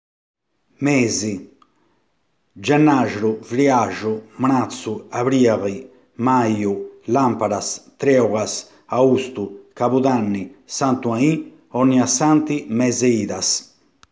ascolta come si dicono i giorno, i mesi e le stagioni nel paese di Senis